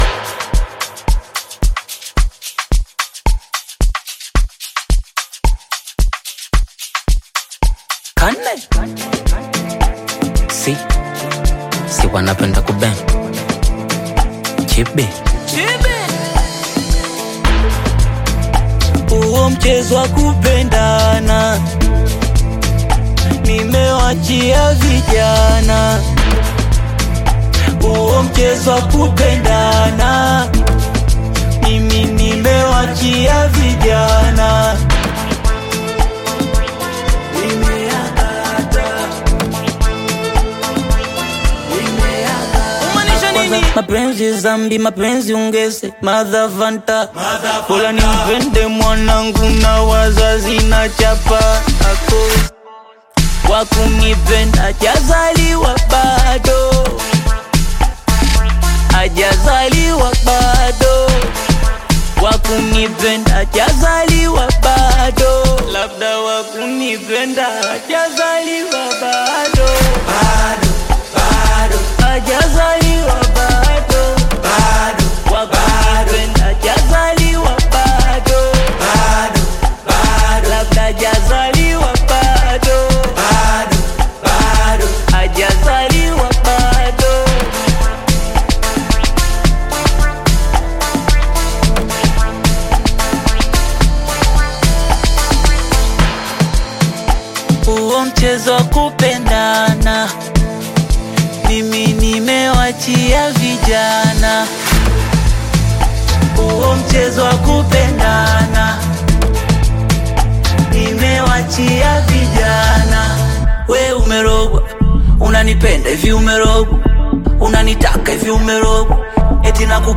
Bongo flava
Duo singers
Bongopiano